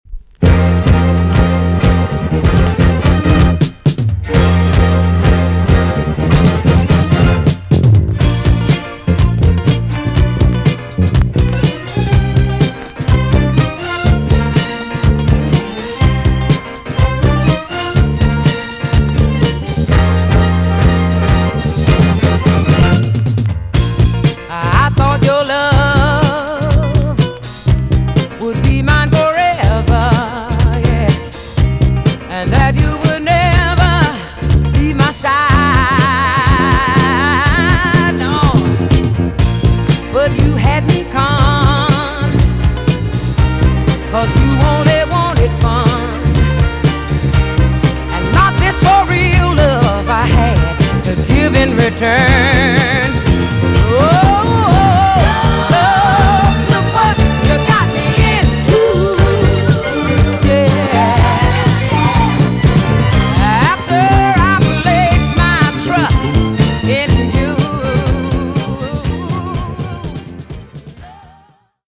TOP >JAMAICAN SOUL & etc
EX- 音はキレイです。